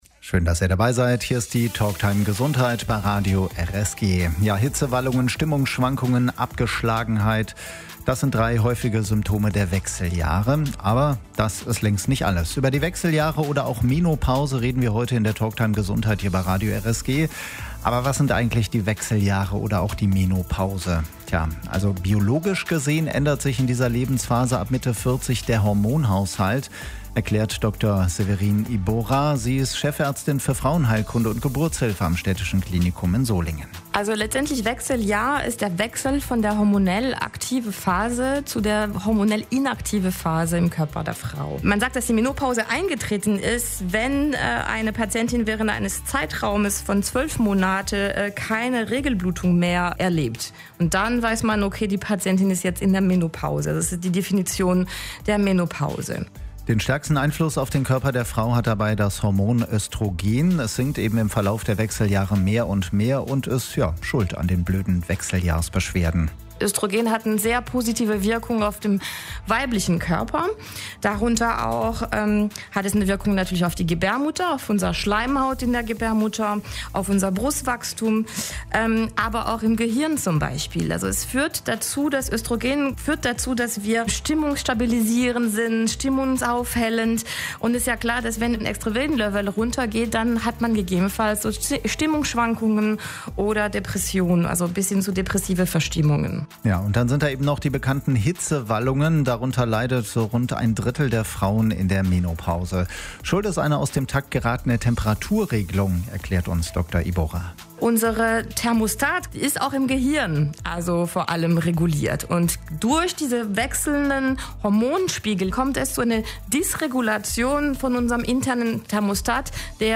Die Talktime Gesundheit lief am Samstag, 21. Juni, von 12 bis 13 Uhr bei Radio RSG - wenn ihr etwas runterscrollt, könnt die Sendung hier noch einmal hören.